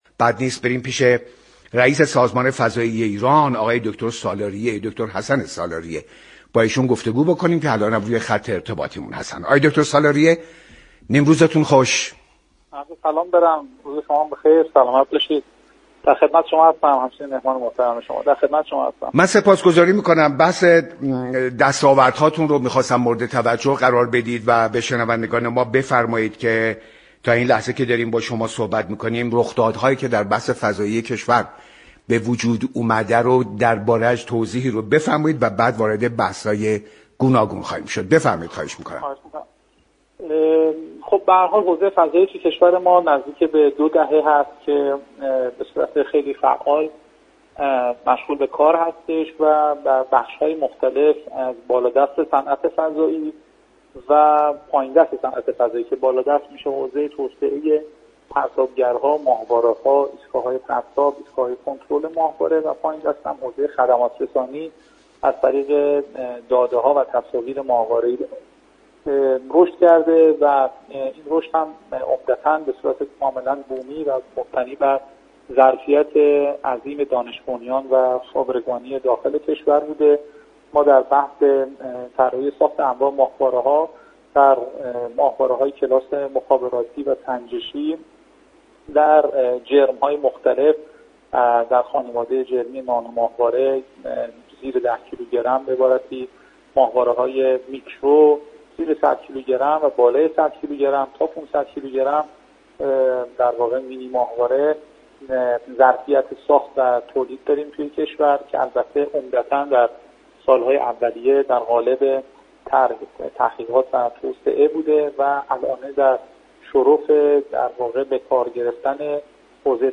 حسن سالاریه رییس فضایی ایران در برنامه ایران امروز گفت: ایران در ساخت انواع ماهواره‌های مخابری و سنجشی ظرفیت‌های تولیدی دارد.